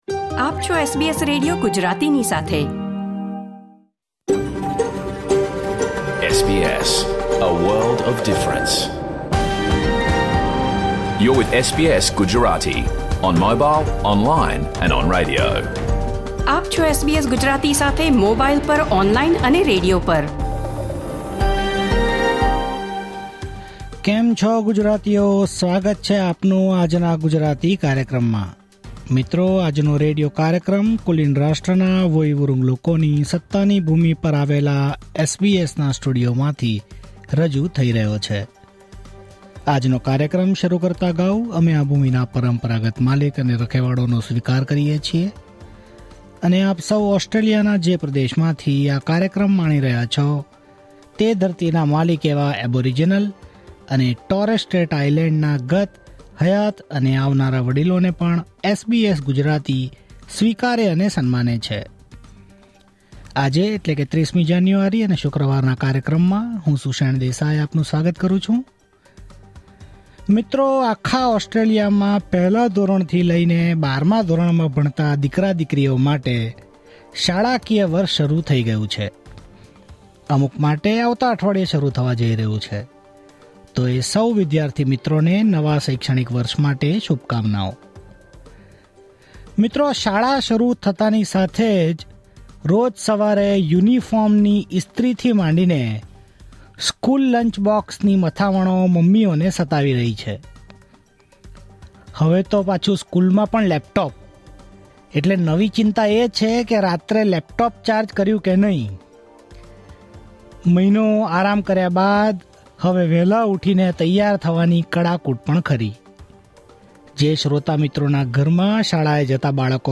Listen to the full SBS Gujarati radio program